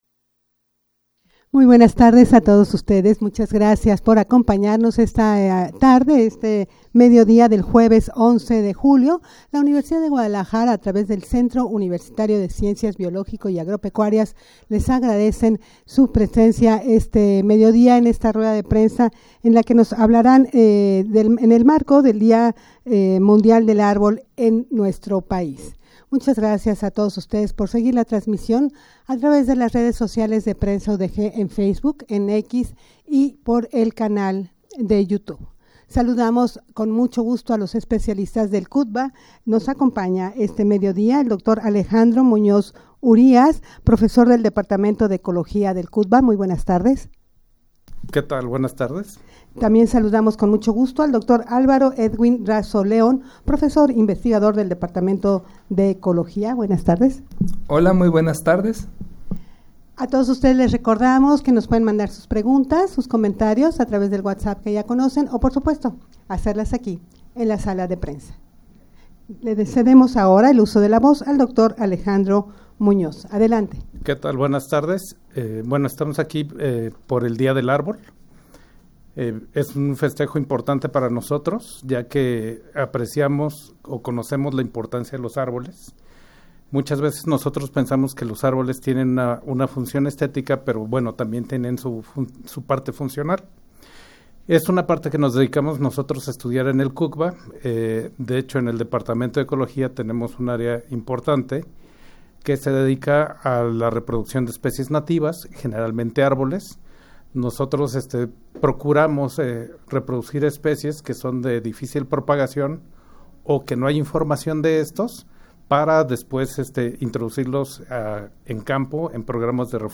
rueda-de-prensa-en-el-marco-del-dia-mundial-del-arbol-en-mexico.mp3